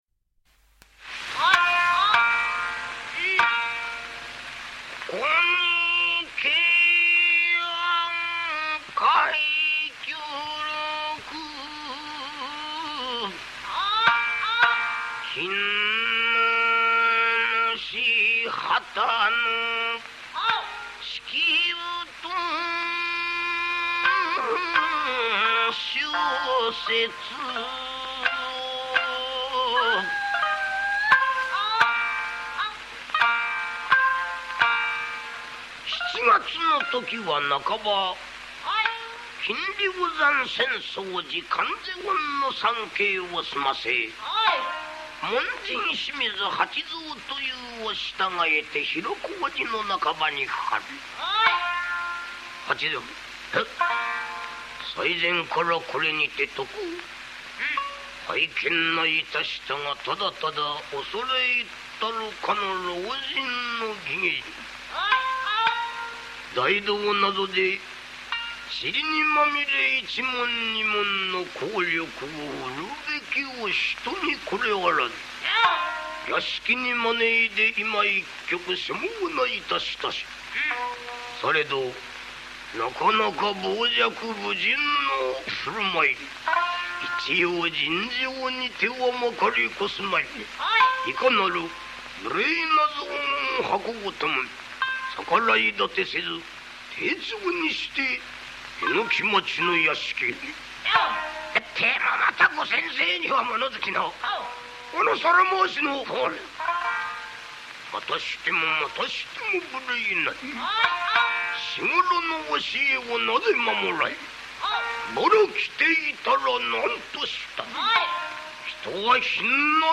初代木村重友　慶安太平記　正雪と式部　M-3（14:00）
その中でも初代木村重友、三代目鼈甲斎虎丸の二人はとりわけ個性的で味わい深い節の芸といえる。
しかし、天性の美声と啖呵の良さでたった1年で真打となった。
今ではあまり使われなくなった啖呵の最後の一行を節で聴かせるあのいなせな関東節。